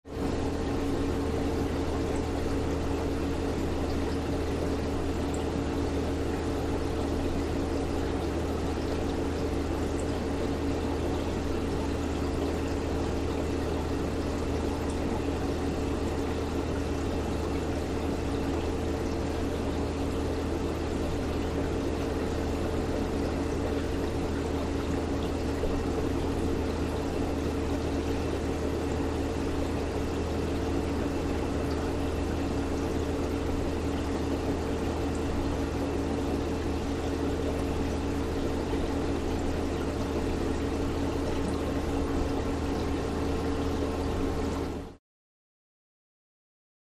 Room Ambience; Aquarium Shop With Aerator Hum, Water Movement In Tanks